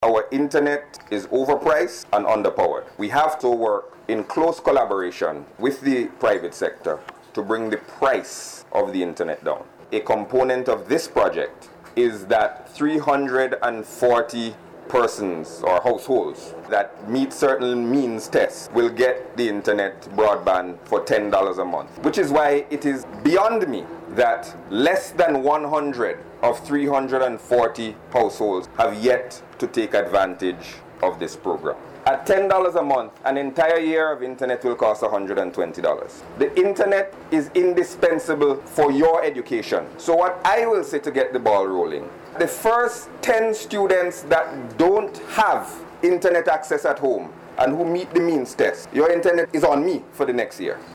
Minister with responsibility for Information Technology, Senator Camillo Gonsalves.